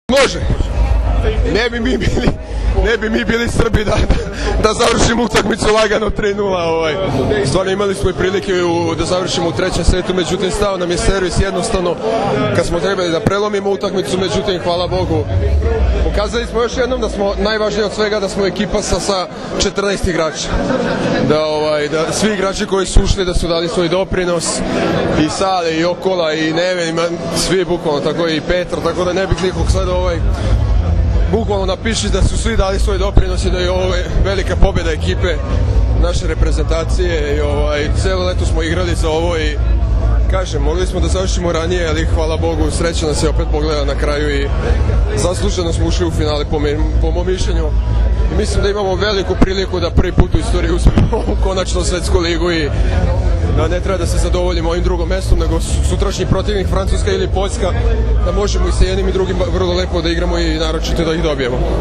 IZJAVA MARKA PODRAŠČANINA